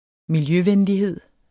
Udtale [ milˈjøˌvεnliˌheðˀ ]